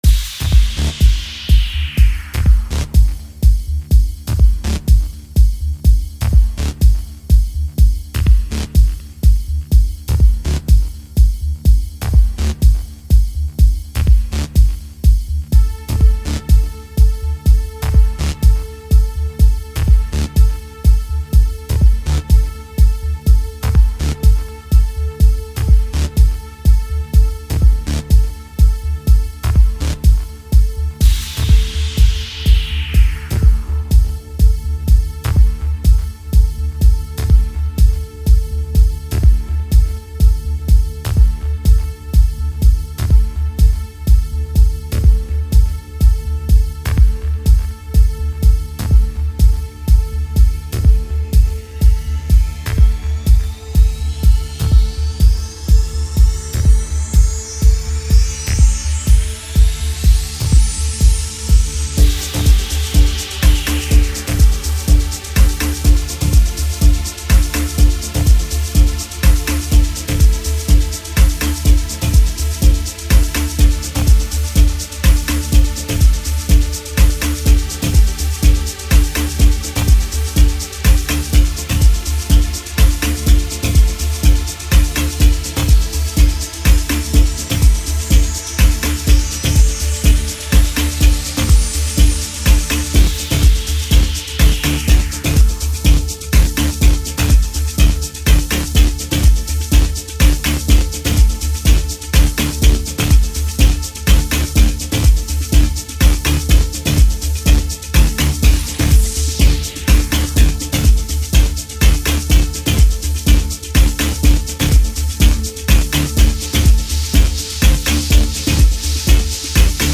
House Music Producer and Disk Jockey